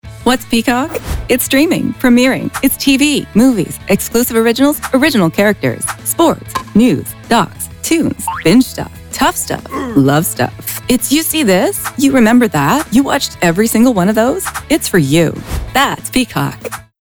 Airbnb: warm, approachable, genuine Mackenzie Investments: confident, professional, trustworthy Dior: elegant, sophisticated, enchanting Knotts Berry Farm: cheerful, upbeat, inviting Peacock TV: vibrant, engaging, dynamic